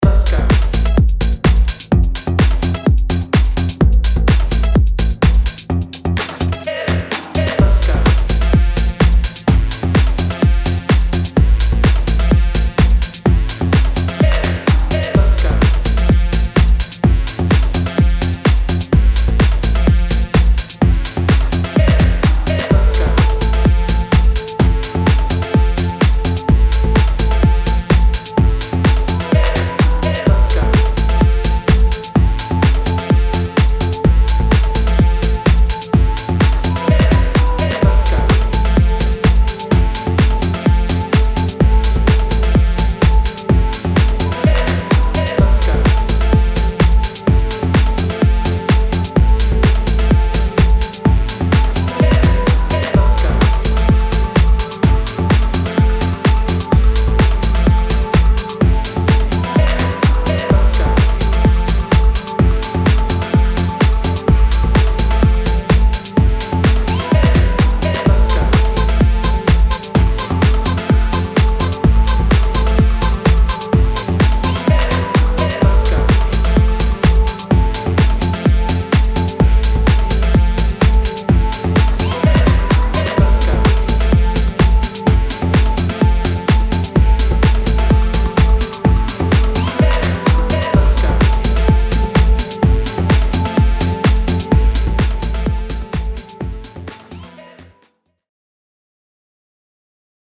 スウィンギンなテック・ハウス・グルーヴにEBMの要素が散りばめられた